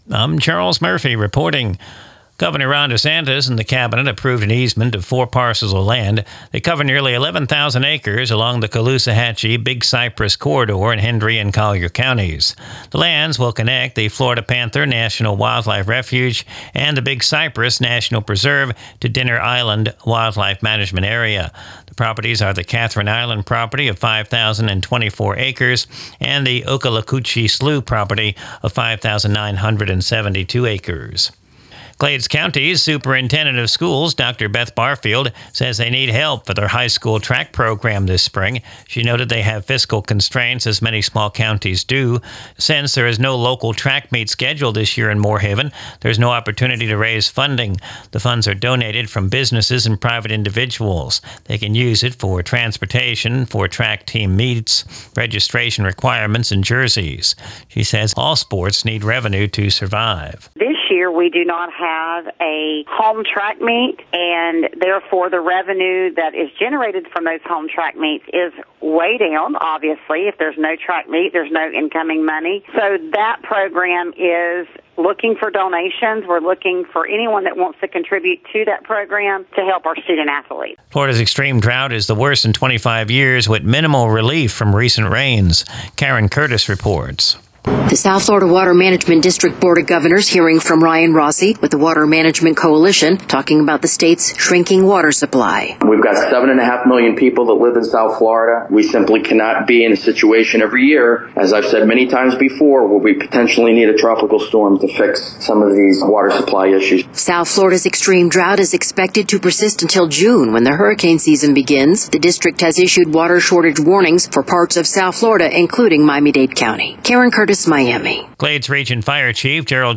Recorded from the WAFC daily newscast (Glades Media).